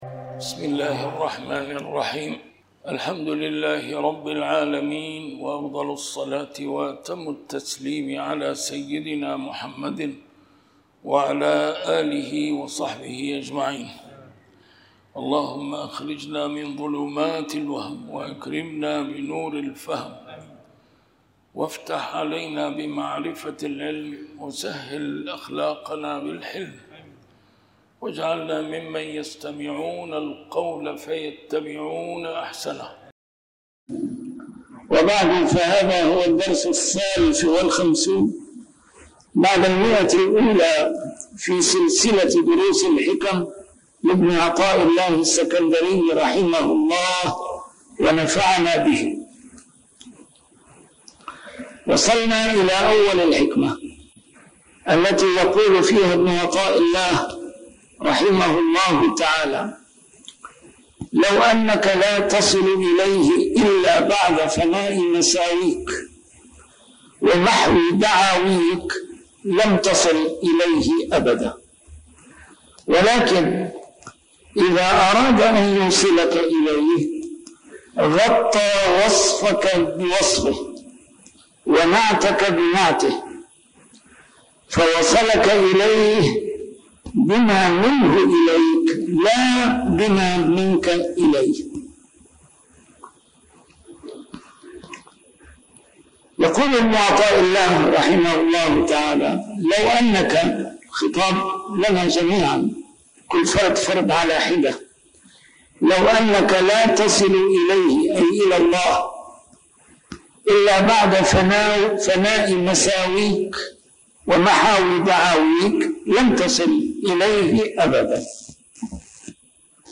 A MARTYR SCHOLAR: IMAM MUHAMMAD SAEED RAMADAN AL-BOUTI - الدروس العلمية - شرح الحكم العطائية - الدرس رقم 153 شرح الحكمة 130